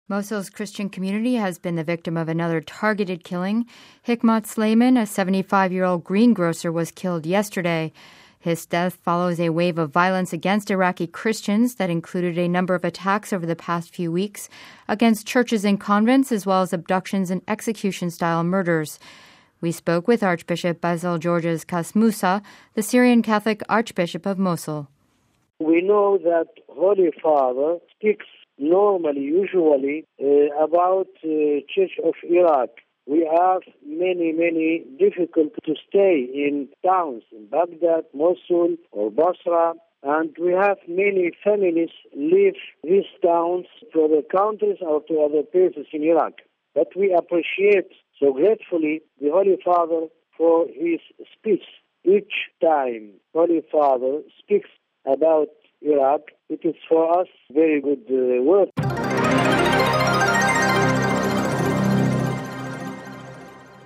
We spoke with Archbishop Basile Georges Casmoussa, Archbishop of Syro Catholic Community of Mosul...